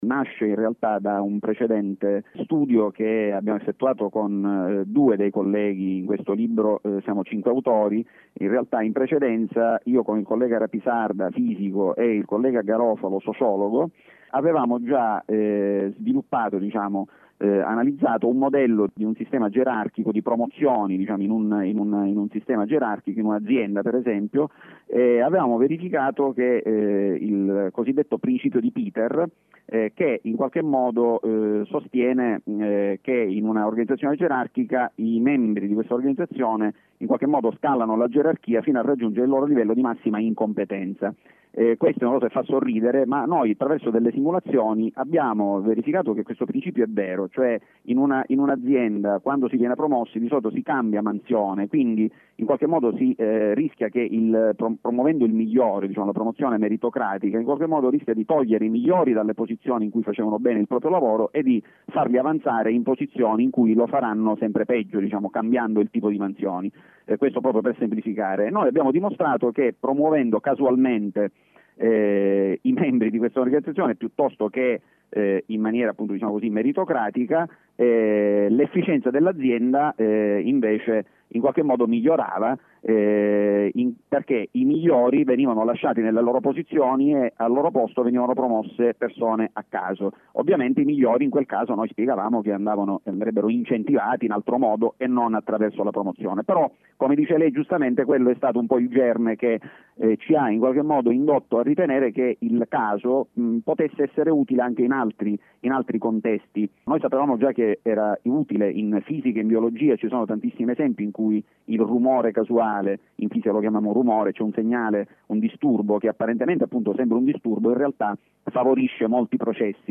Intervista radiofonica su Ecoradio
Ecoradio-intervista-democrazia-a-sorte.mp3